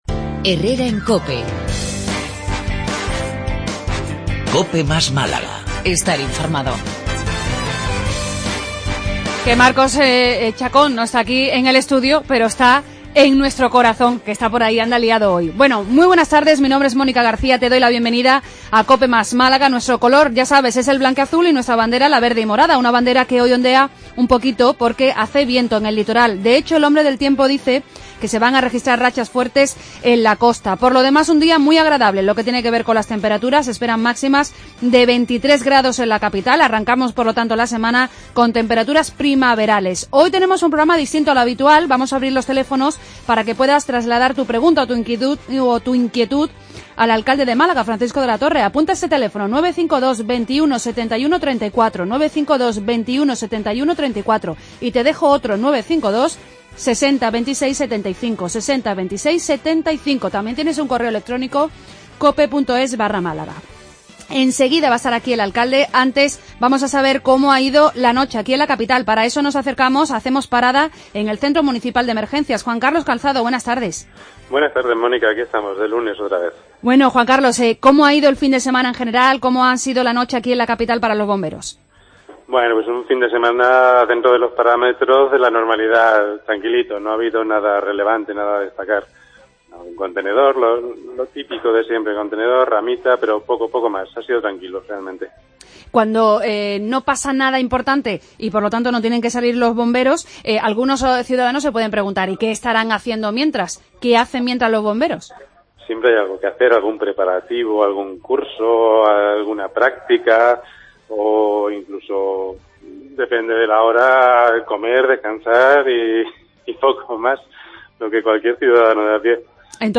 Hoy con preguntas de nuestros oyentes al alcalde de nuestra ciudad, D. Francisco de la Torre.